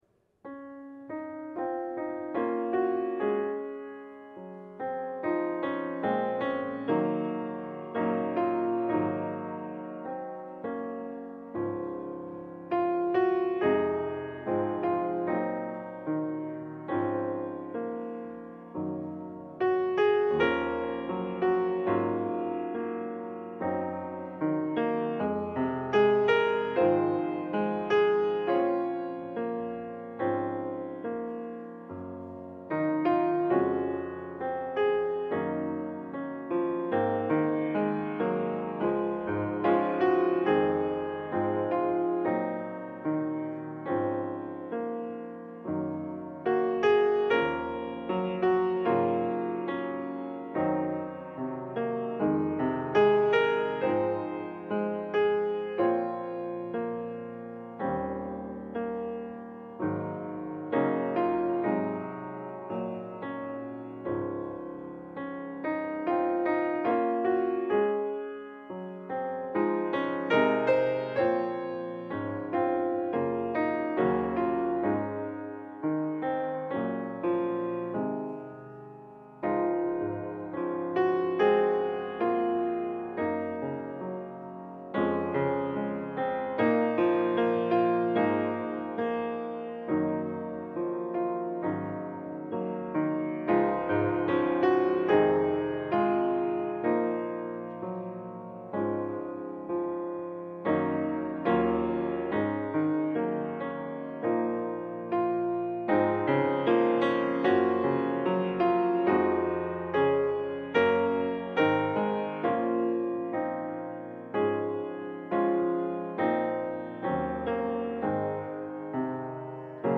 LIVE Morning Service - The Word in the Windows: The Widow's Mite